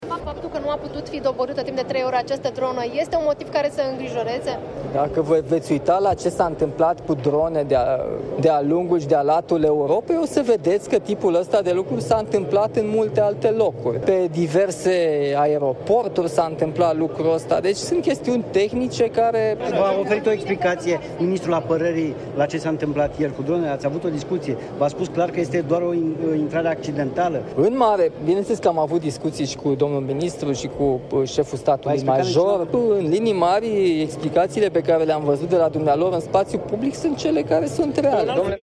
Declarații pe holurile parlamentului, venite de la șeful statului, după ce a prezentat Strategia Națională de Apărare.
Jurnaliștii au insistat cu întrebări. Au cerut clarificări privind motivele pentru care dronele nu sunt doborâte și au încercat să afle dacă ministrul Apărării a prezentat concluziile incidentelor de ieri.